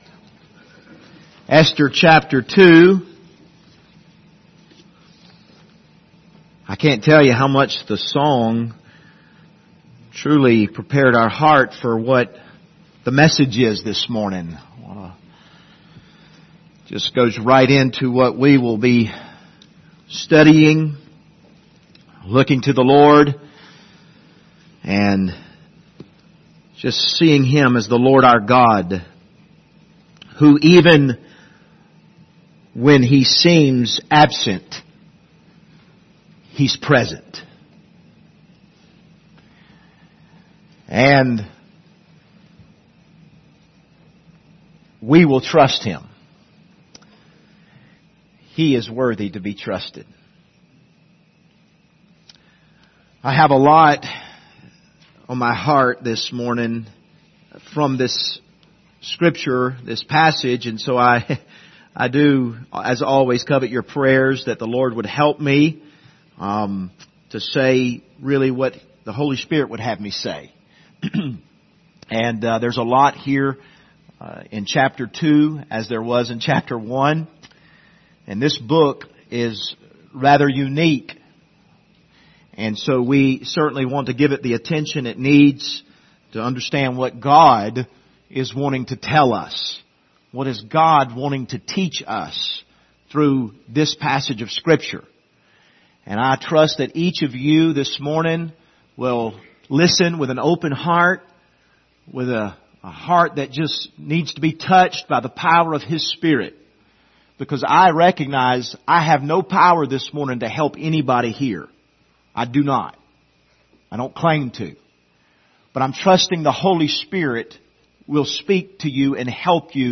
Passage: Esther 2 Service Type: Sunday Morning